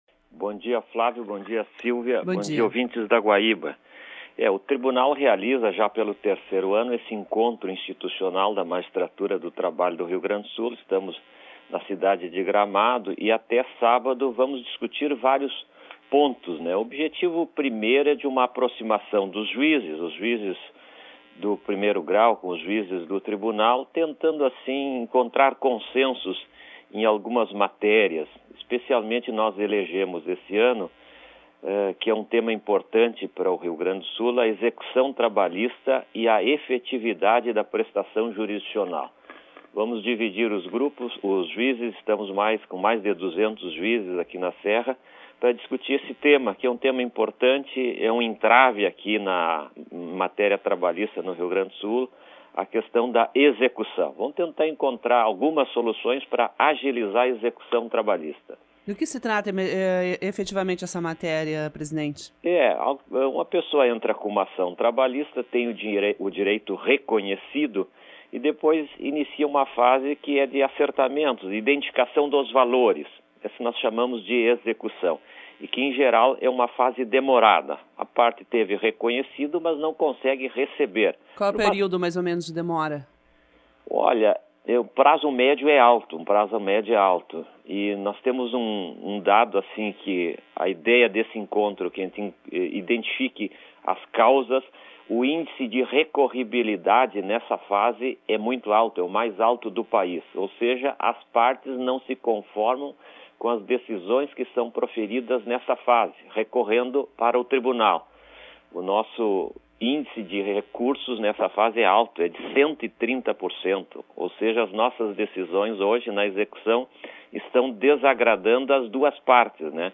Rádio Guaíba AM: Presidente concede entrevista, direto de Gramado
Clique no ícone de áudio, acima, à direita do título, e ouça a entrevista (5'45") concedida pelo Presidente João Ghisleni Filho, ao programa Agora, veiculado de segunda a sexta-feira, das 9h10min às 10h30min, na Rádio Guaíba AM (720 kHz).
09 - Ghisleni na Guaiba.mp3